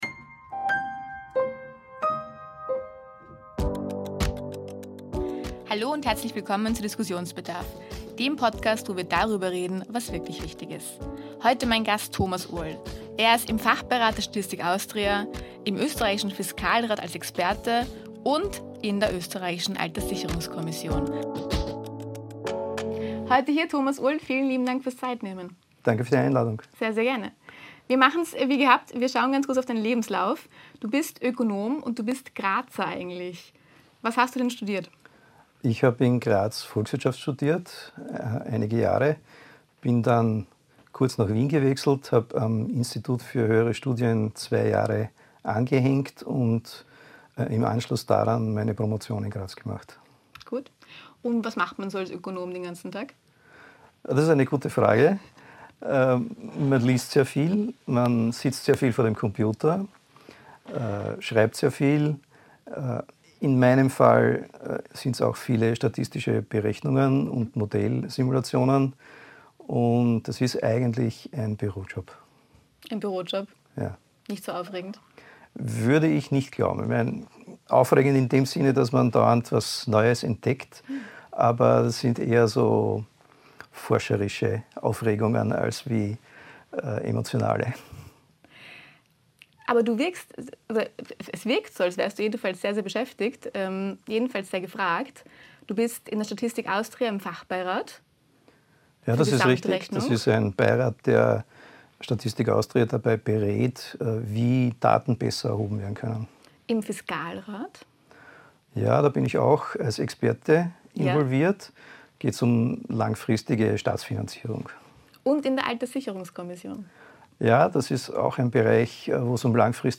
Produktion: T3 Podcaststudio Wien